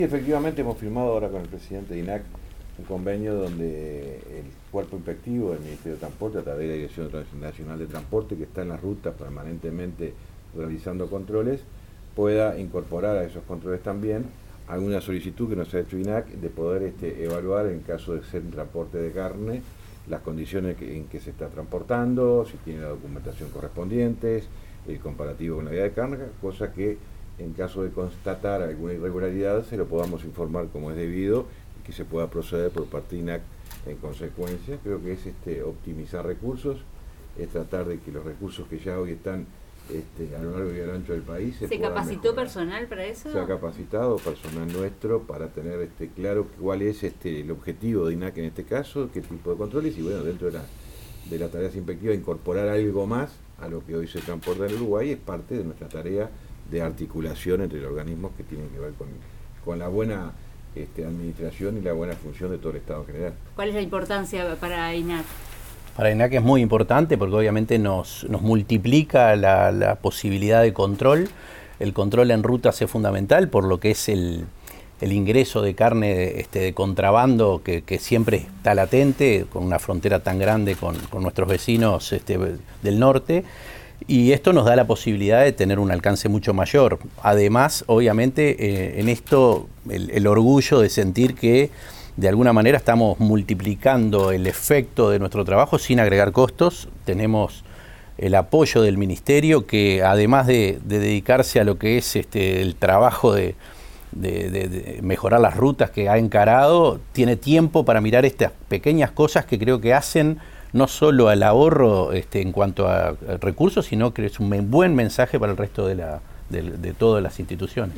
Entrevista periodística- mp3